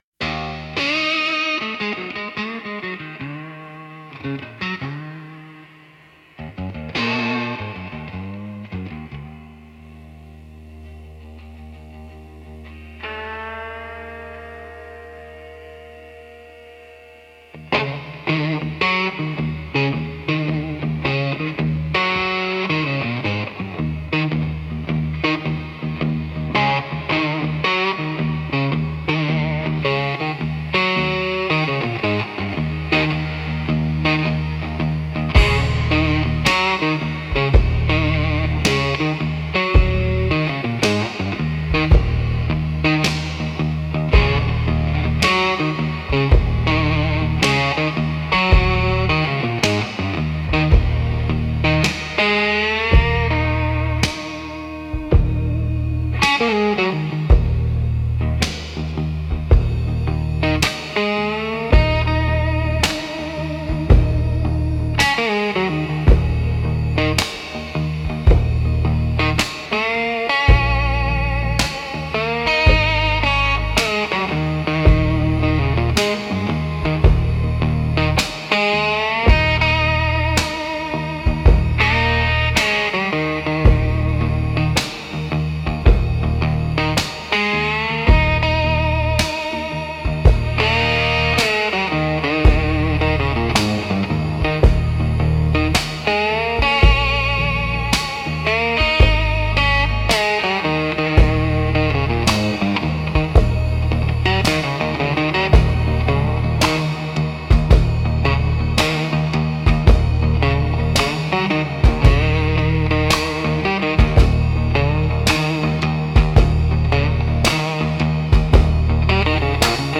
Instrumental - Gravel Road Beatdown